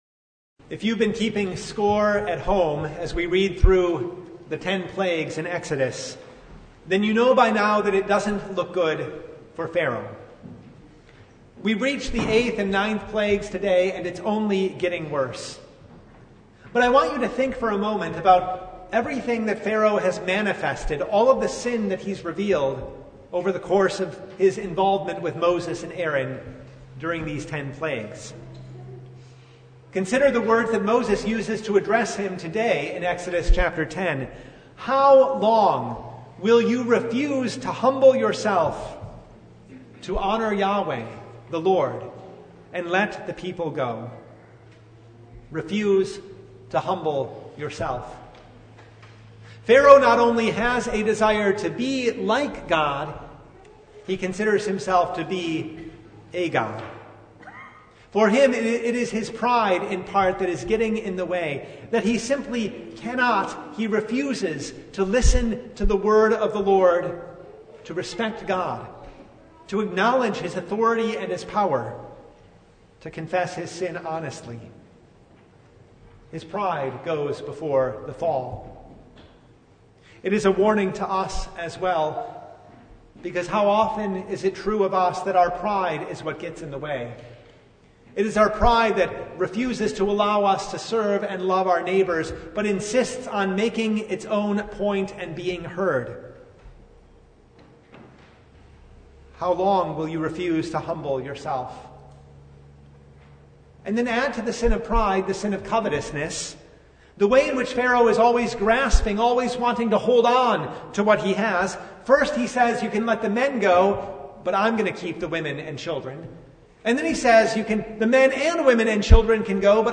Exodus 10:1-29 Service Type: Lent Midweek Noon Topics: Sermon Only